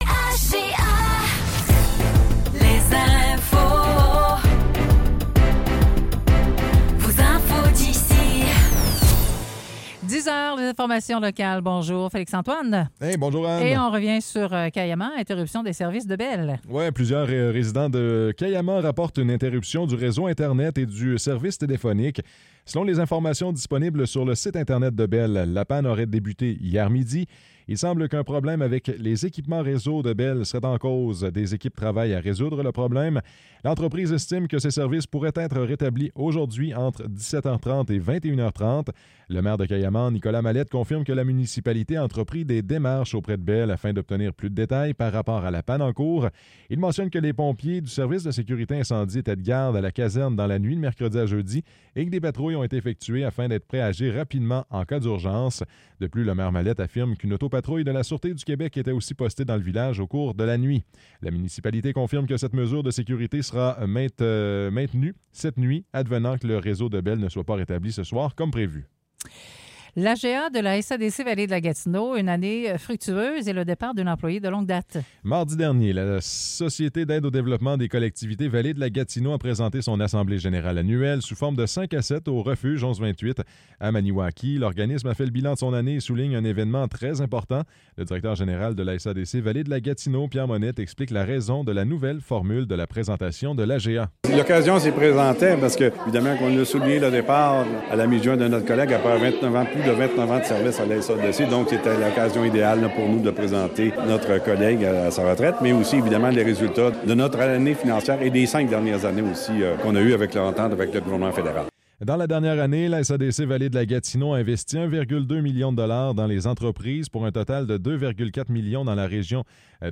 Nouvelles locales - 30 mai 2024 - 10 h